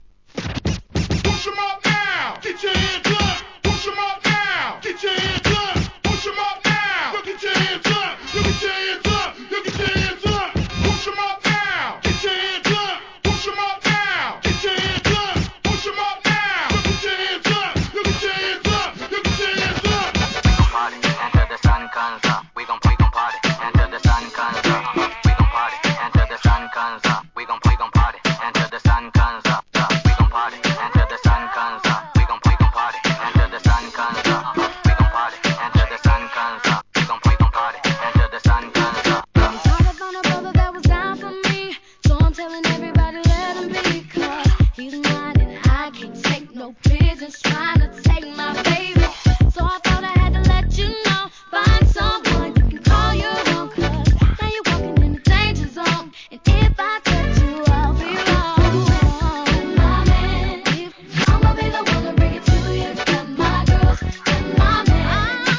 HIP HOP/R&B
2002年ヒット曲のMEGAMIX物!!